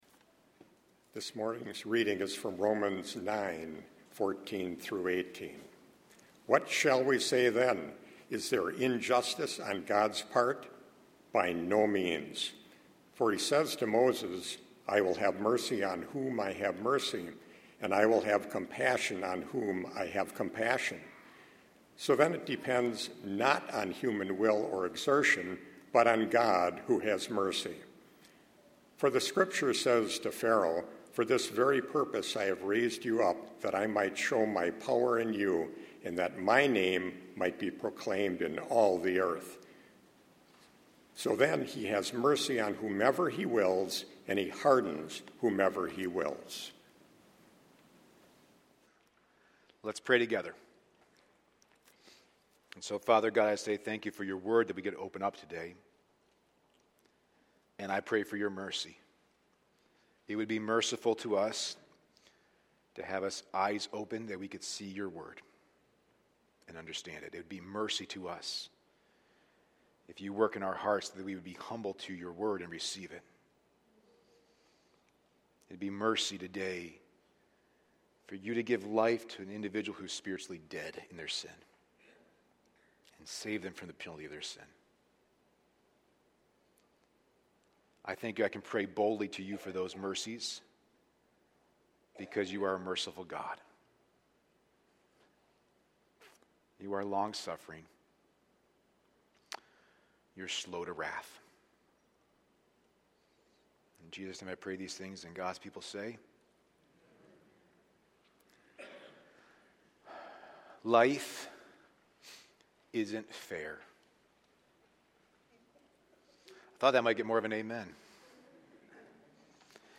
A sermon from the series "Romans."